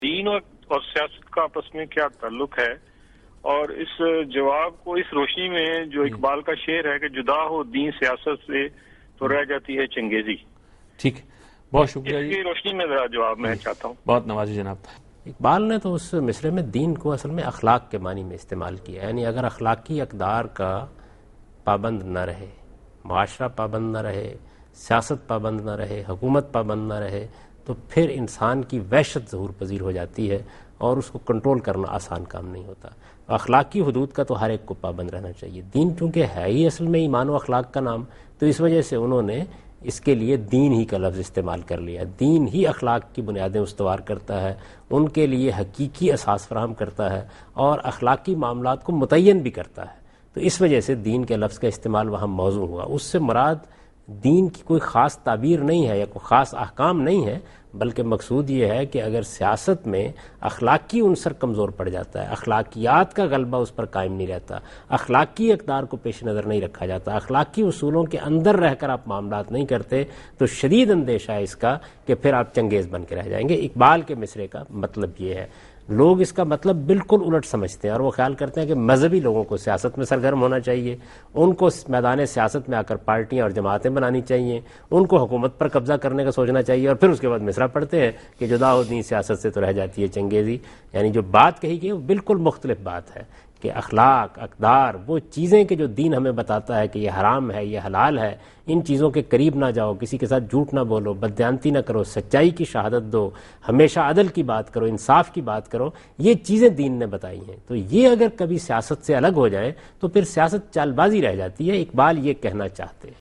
Category: TV Programs / Dunya News / Deen-o-Daanish /